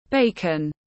Thịt ba chỉ xông khói tiếng anh gọi là bacon, phiên âm tiếng anh đọc là /ˈbeɪkən/
Bacon /ˈbeɪkən/